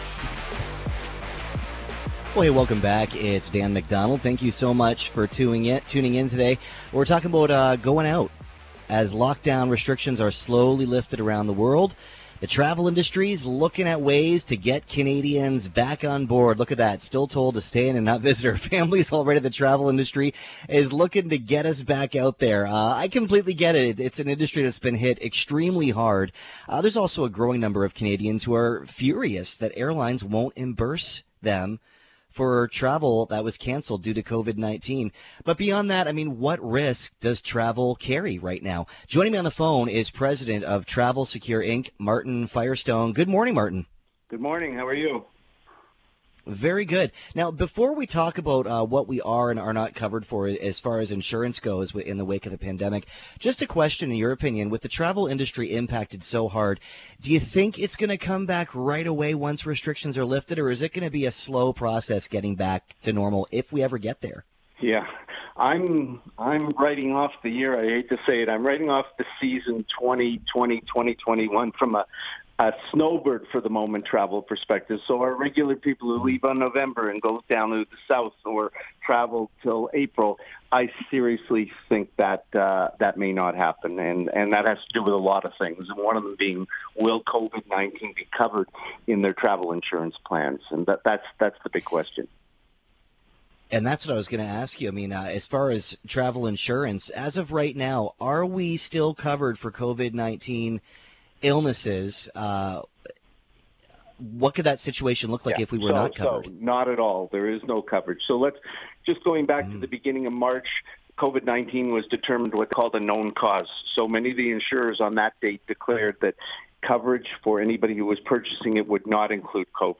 Interview on AM800!